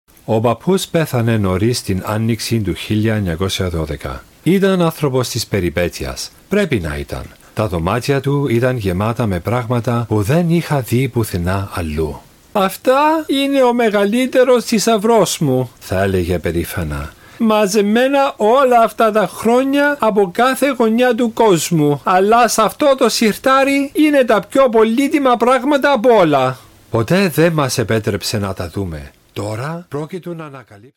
Greek, Male, Home Studio, 30s-50s